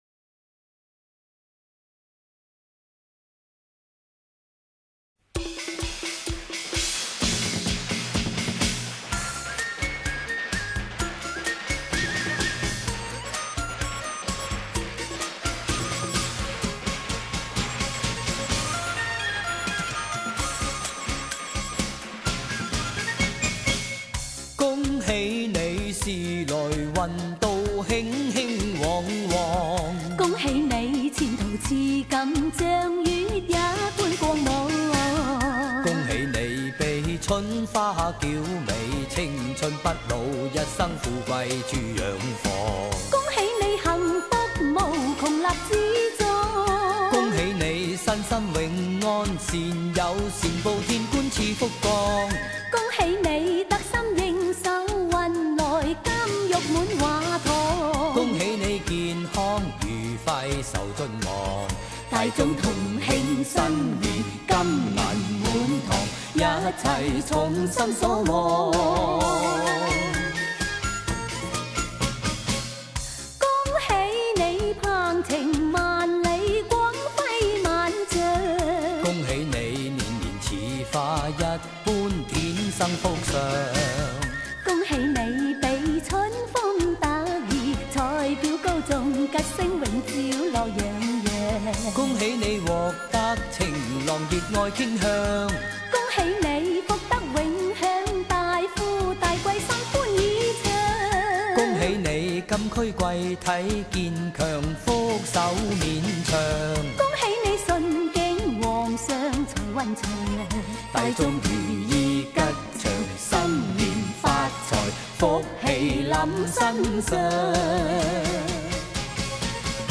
粤语小调